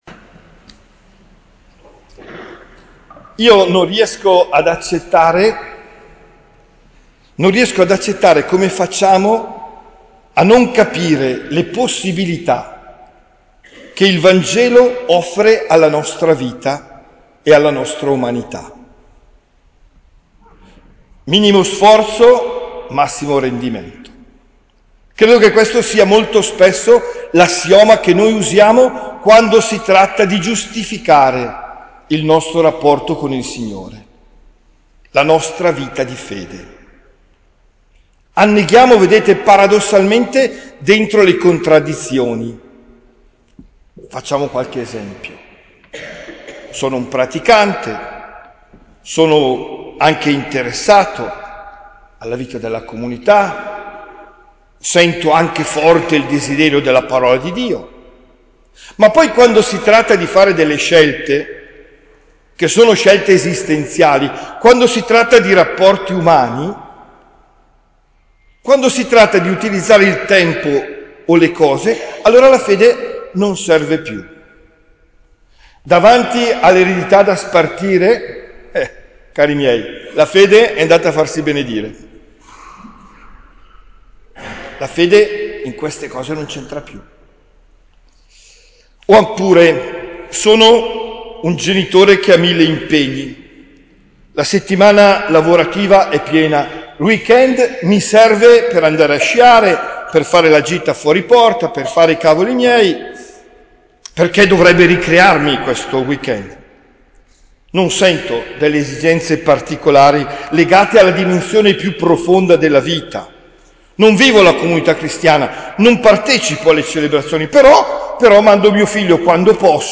OMELIA DEL 12 FEBBRAIO 2023